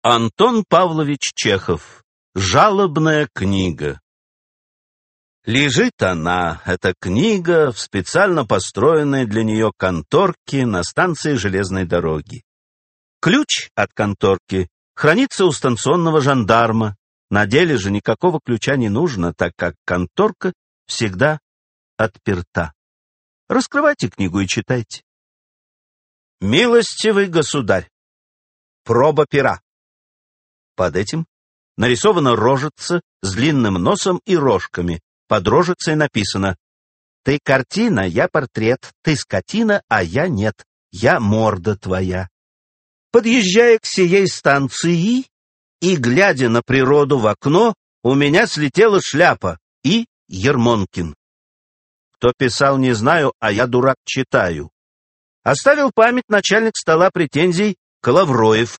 Аудиокнига Жалобная книга (сборник юмористических рассказов) | Библиотека аудиокниг